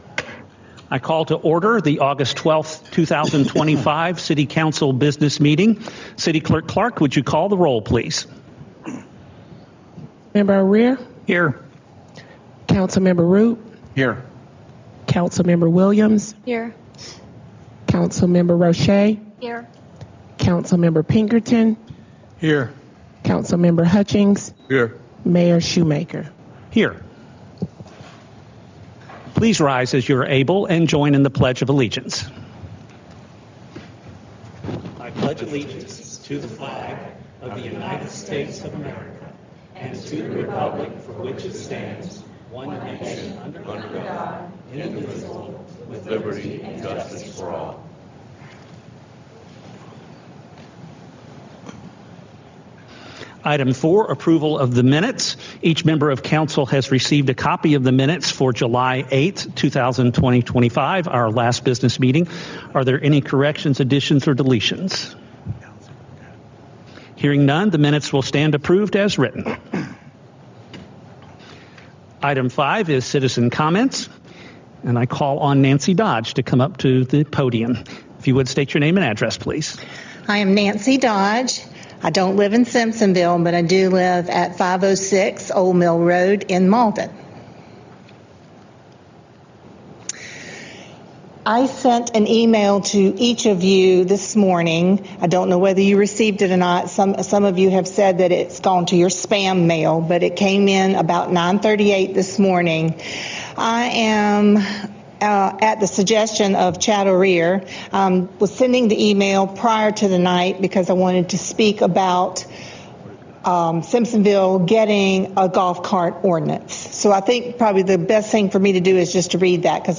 Simpsonville City Council held a regularly scheduled business meeting on Aug. 12, 2025 in Council Chambers a.k.a. “The Ellipse.”
Present: Councilman Chad O’Rear (Ward 1), Councilman Aaron Rupe (Ward 2), Councilwoman Shannon Williams (Ward 3), Councilwoman Sherry Roche (Ward 4), Councilman Tim Pinkerton (Ward 5), Councilman Lou Hutchings (Ward 6), Mayor Paul Shewmaker
08-12-Business-Meeting.mp3